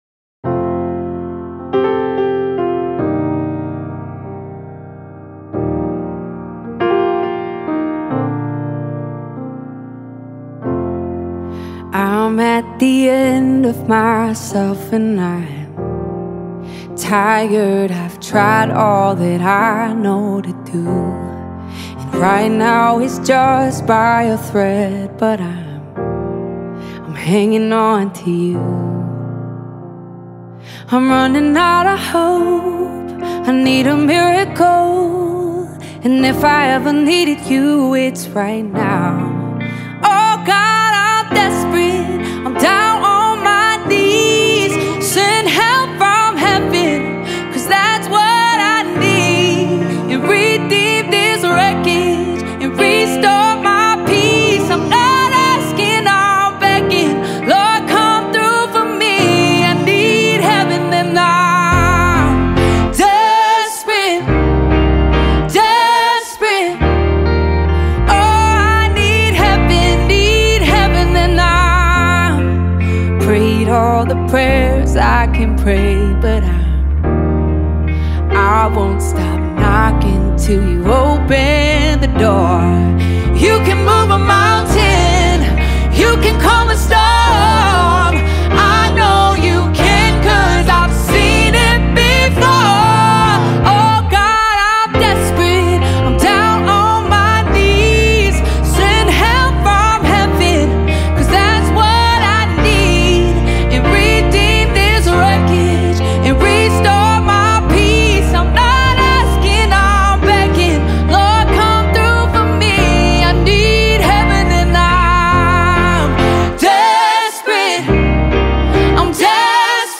249 просмотров 114 прослушиваний 13 скачиваний BPM: 70